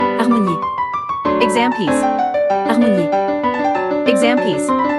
• 人声数拍
• 大师演奏范例